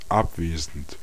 Ääntäminen
IPA: [ap.sɑ̃]